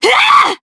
DarkFrey-Vox_Attack4_jp.wav